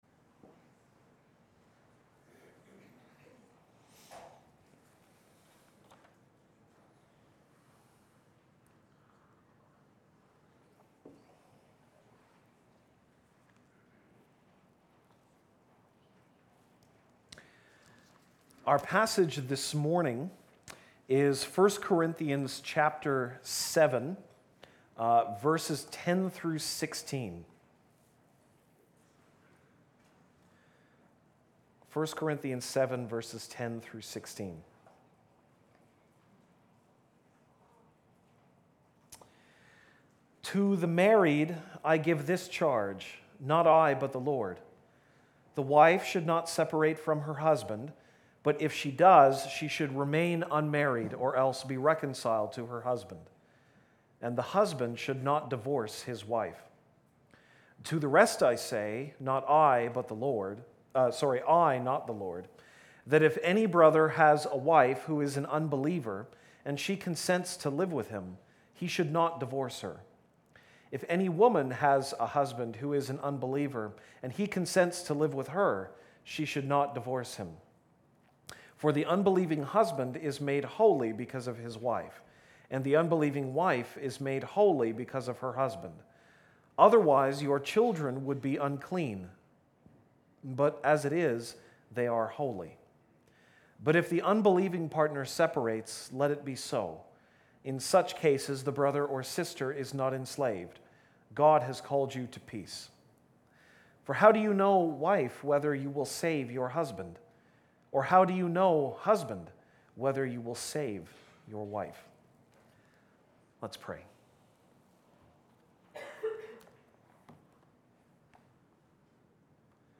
August 26, 2018 (Sunday Morning)